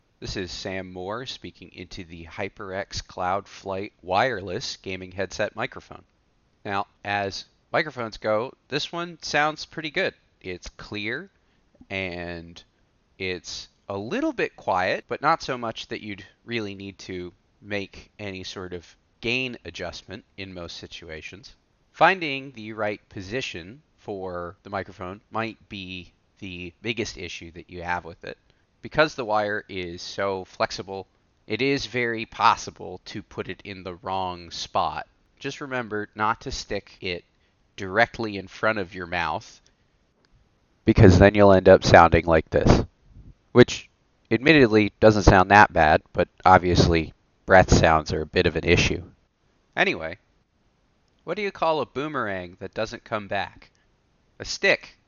HyperX-Cloud-Flight-Wireless-mic-sample.mp3